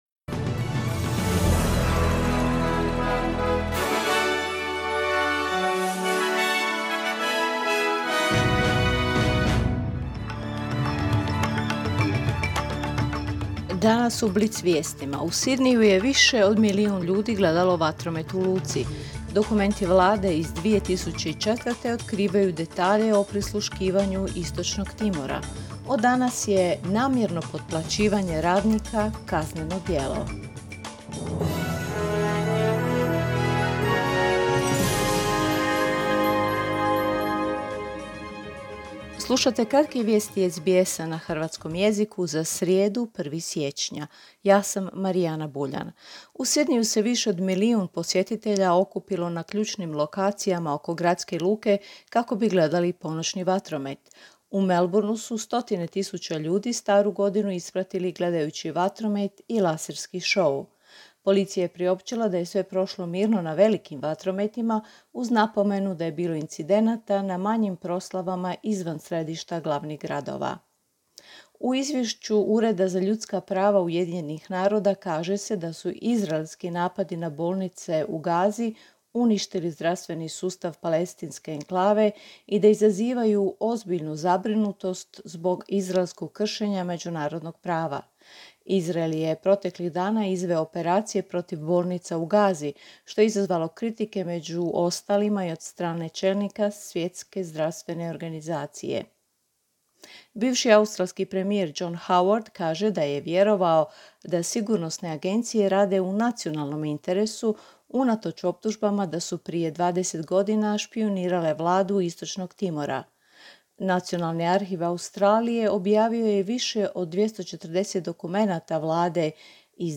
Vijesti radija SBS.